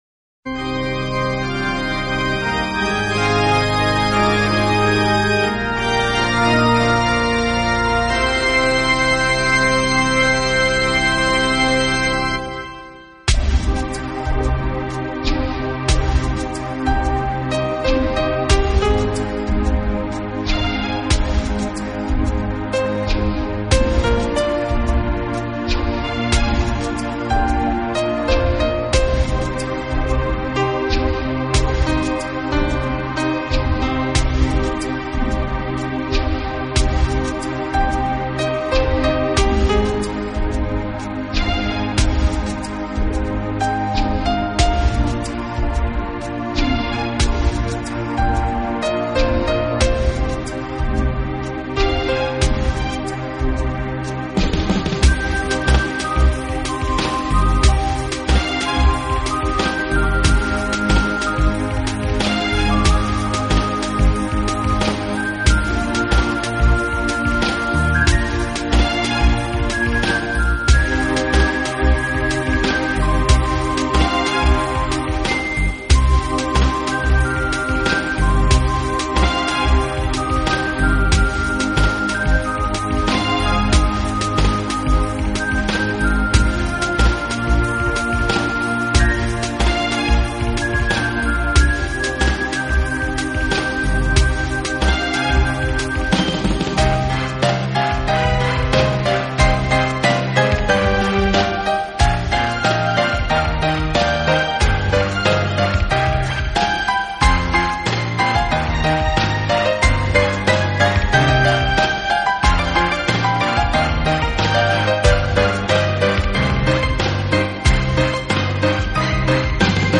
Genre ............. : New Age/Piano
solo instrumental album
uplifting background music.